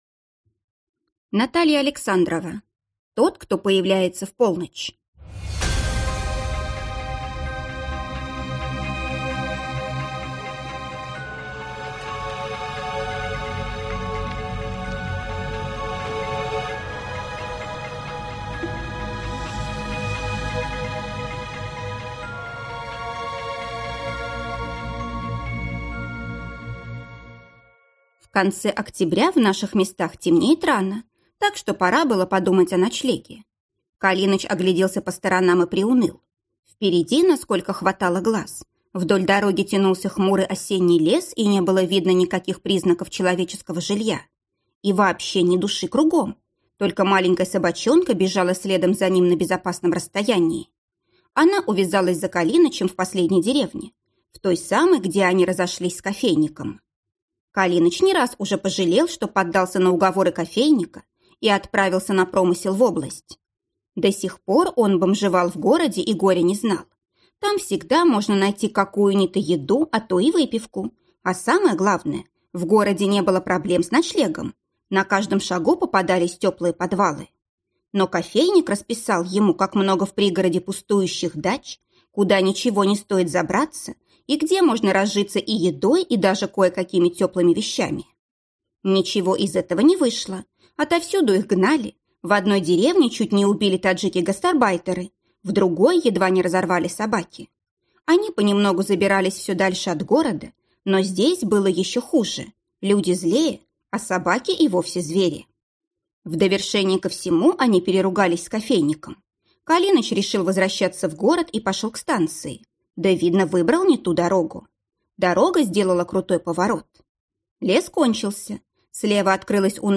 Аудиокнига Тот, кто появляется в полночь | Библиотека аудиокниг